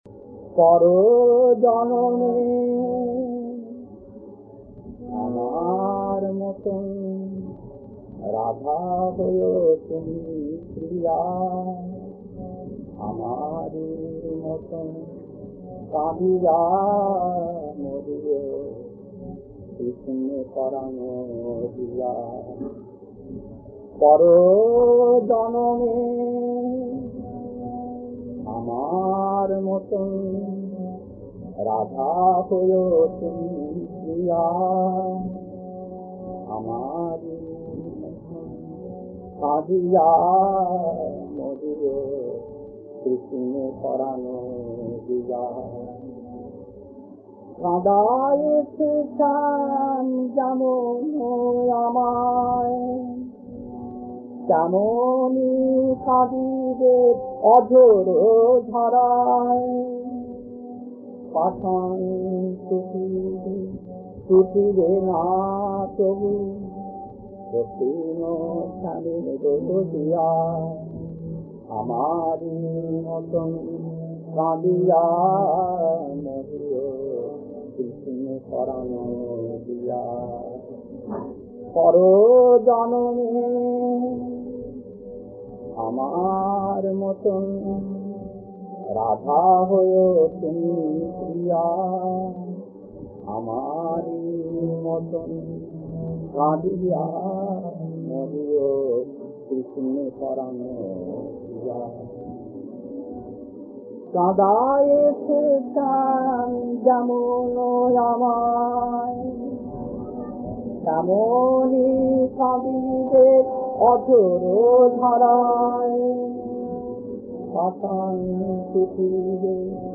Kirtan C7-2 Bombay, Late 80's, 37 minutes 1.